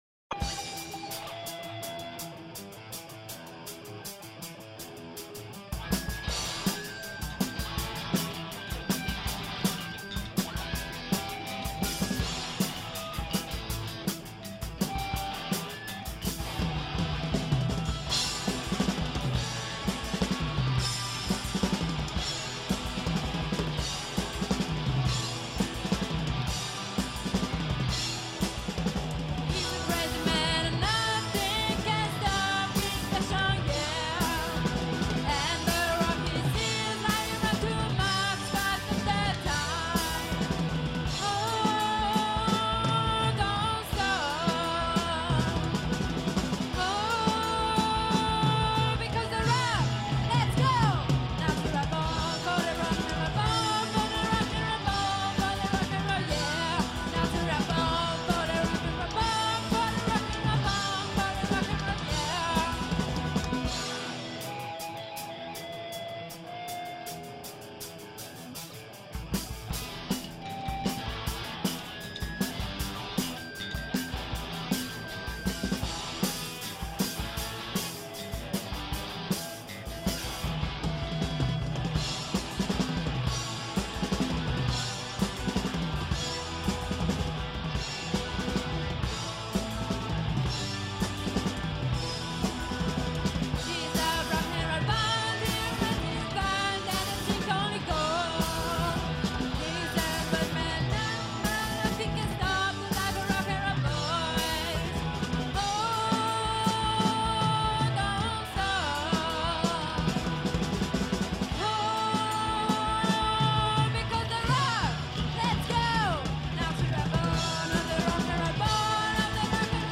Genere: Rock
chitarra
basso
tastiera
voce
batteria
Sono disponibili all'ascolto due brani inediti registrati durante le prove.